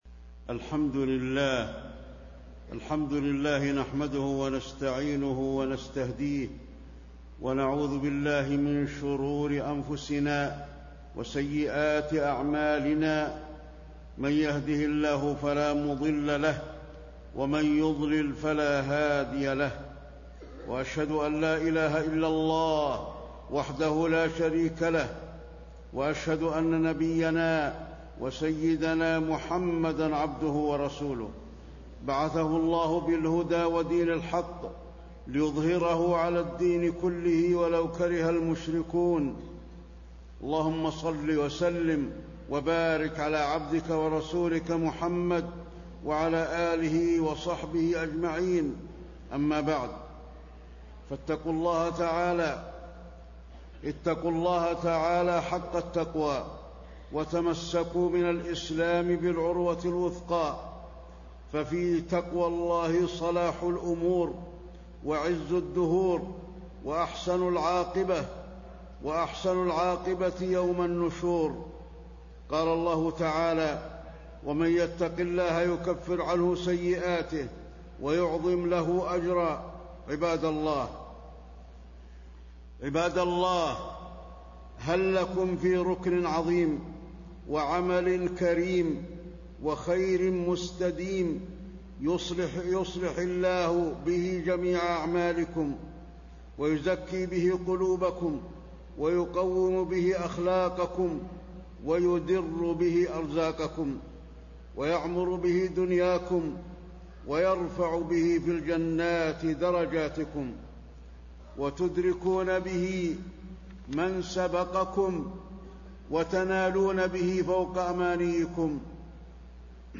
تاريخ النشر ١٧ جمادى الأولى ١٤٣٤ هـ المكان: المسجد النبوي الشيخ: فضيلة الشيخ د. علي بن عبدالرحمن الحذيفي فضيلة الشيخ د. علي بن عبدالرحمن الحذيفي الصلاة الركن العظيم